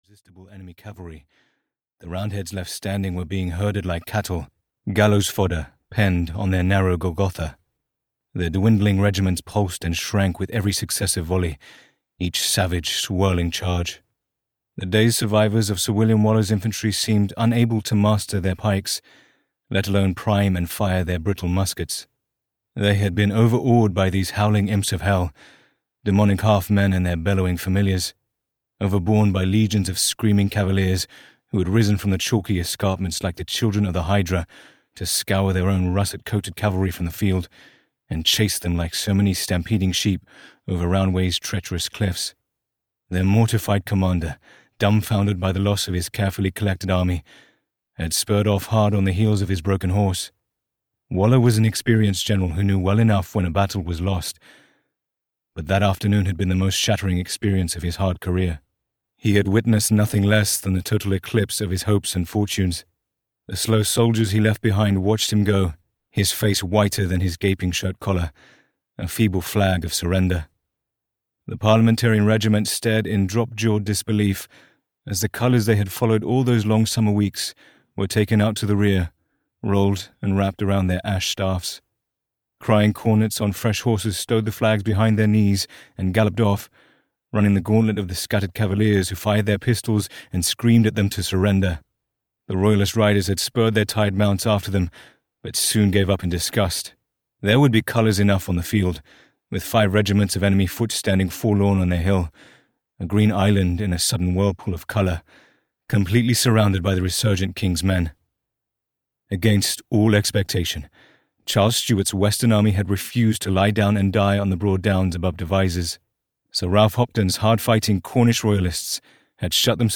Storming Party (EN) audiokniha
Ukázka z knihy